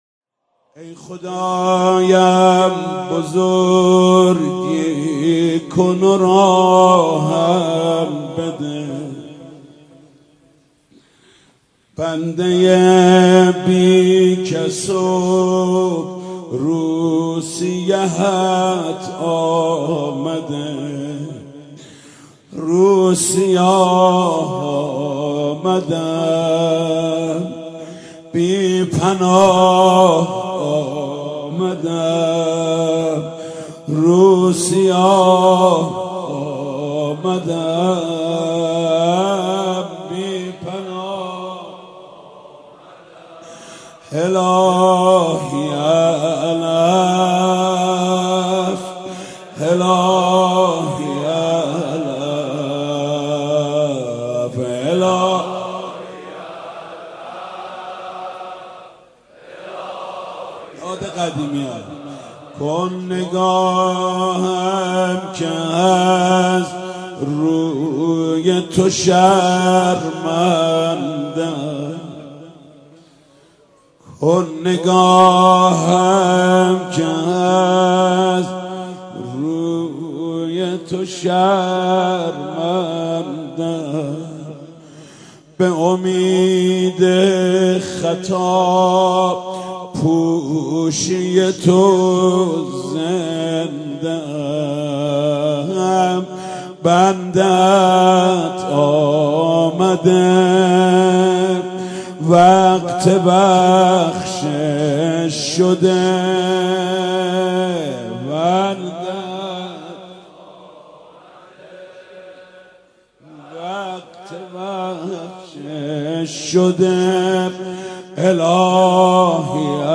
از این رو نوید شاهد تهران بزرگ صوتی از مناجات با خدا در شب بیست و سوم ماه رمضان را برای علاقمندان منتشر می کند.